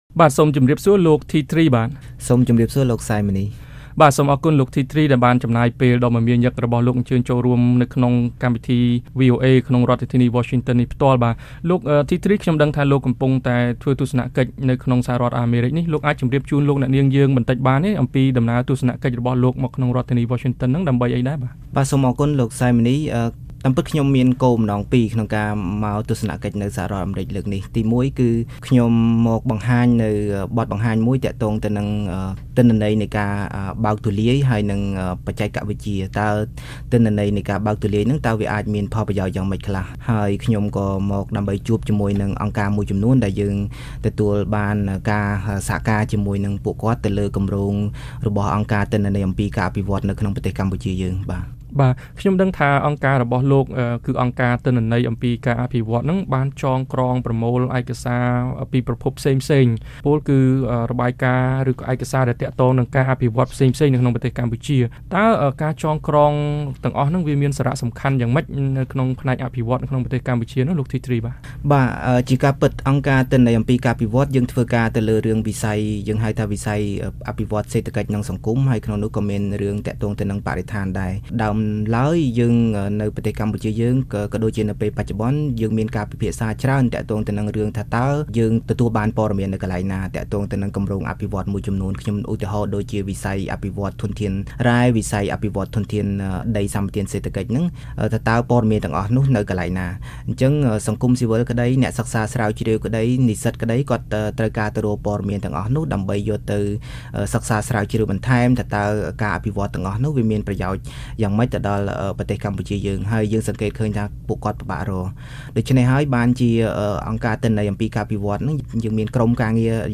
បទសម្ភាសន៍VOA៖ អង្គការក្រៅរដ្ឋាភិបាល បារម្ភចំពោះការកាត់ជំនួយអន្តរជាតិរបស់អាមេរិក